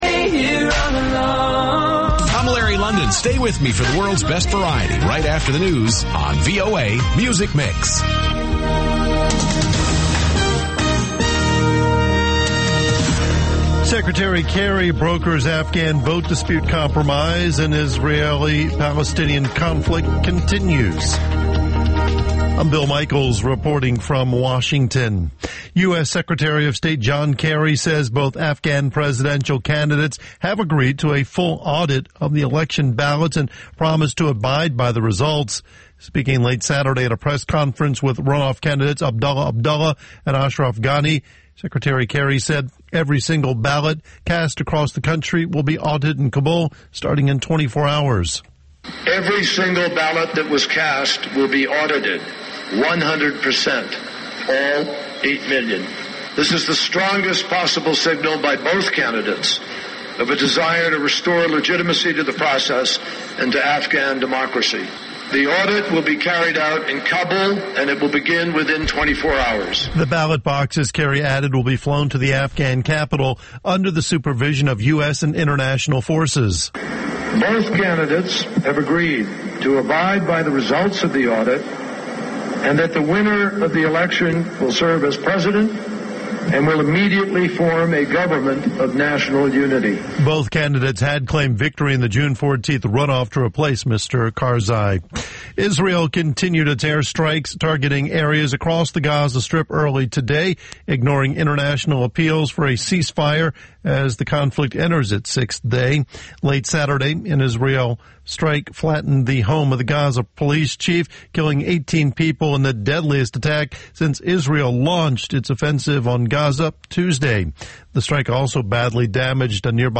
"ამერიკის ხმის" ახალი ამბები (ინგლისურად) + VOA Music Mix